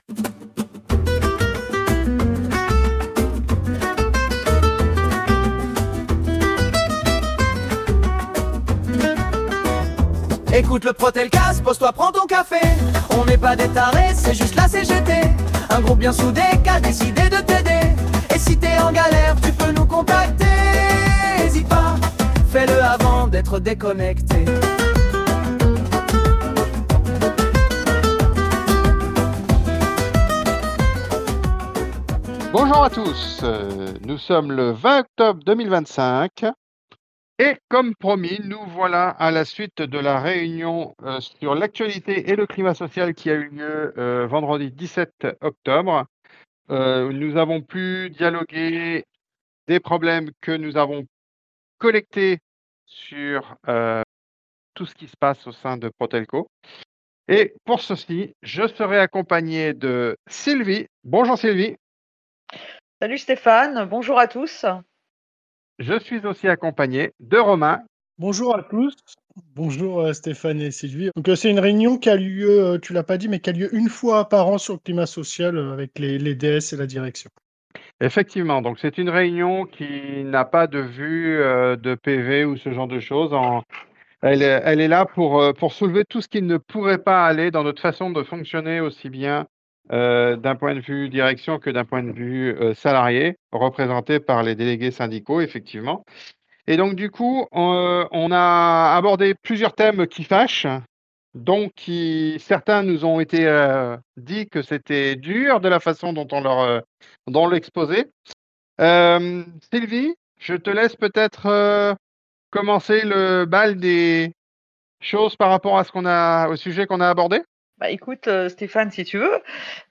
Le refrain est utilisé pour l’introduction et, pour ceux qui souhaitent l’écouter en entier, le morceau complet sera disponible à la fin.